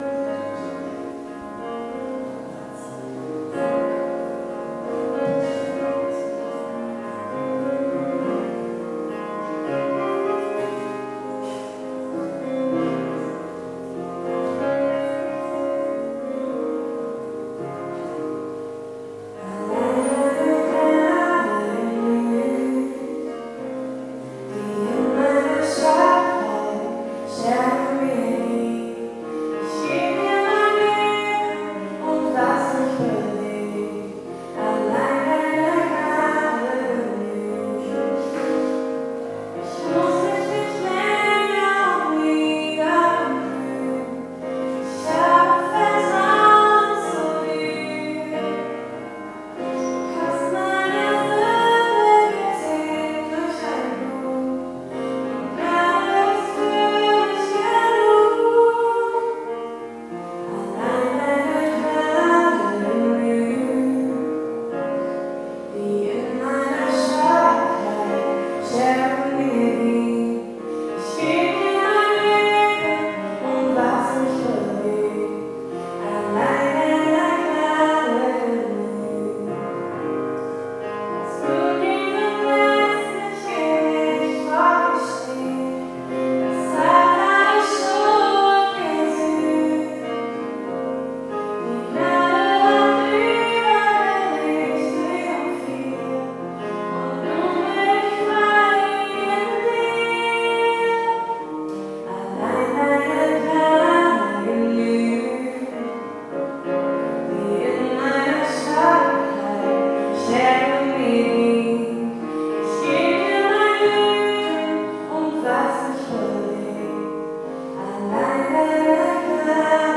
Gottesdienst vom Sonntag, den 4.
aus der evangelischen Kirche Naunheim